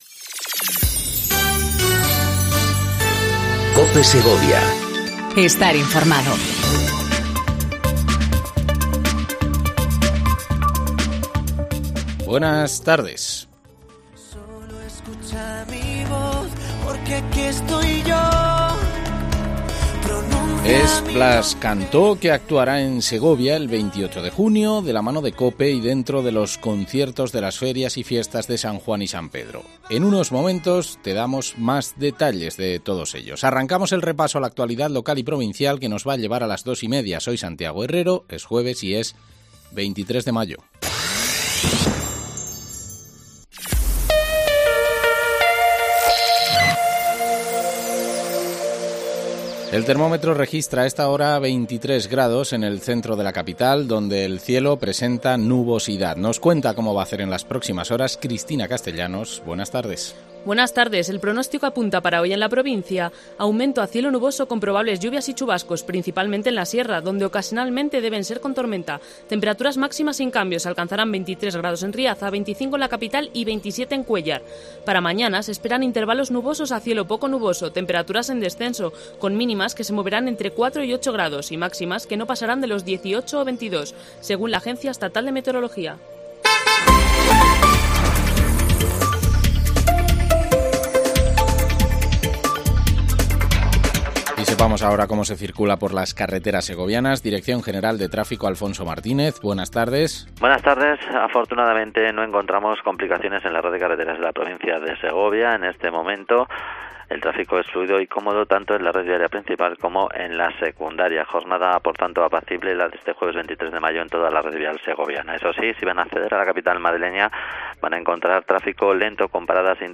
INFORMATIVO DEL MEDIODÍA EN COPE SEGOVIA 14:20 DEL 23/05/19